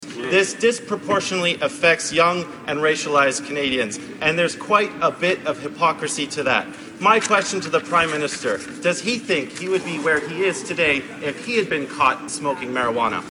Alistair MacGregor spoke to the issue in the House of Commons.